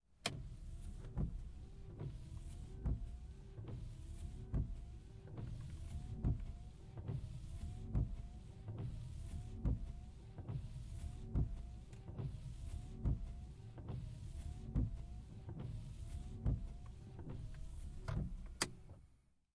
机械 " 雨刮器1
描述：汽车挡风玻璃雨刷不停，几辆汽车在潮湿的道路上经过。
Tag: 汽车 汽车 湿 窗口 挡风玻璃 雨刷